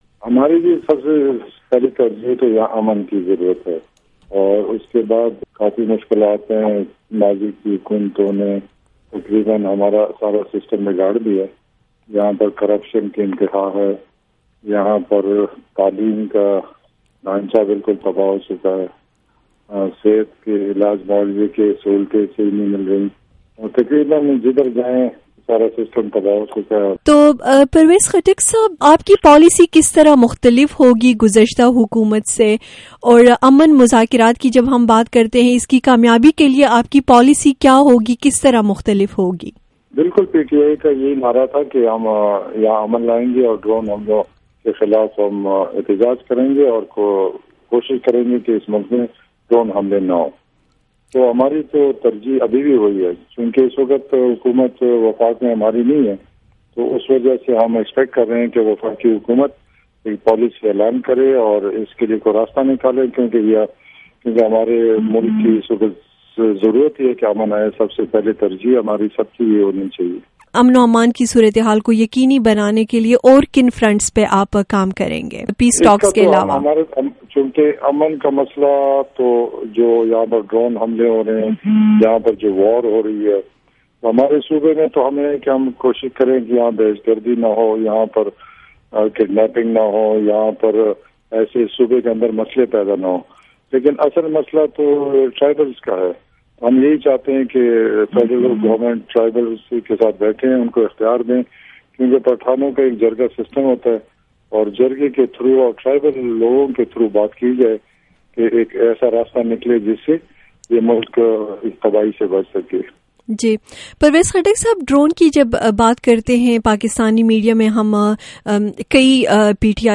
خیبر پختونخواہ کے نئے وزیر اعلیٰ، پرویز خٹک سے خصوصی انٹرویو
Exl. interview with new CM KPK, Parvez Khattak